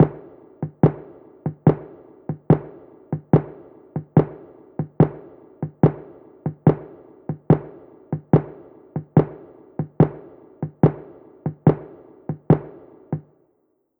I Dont Care Bongo Loop.wav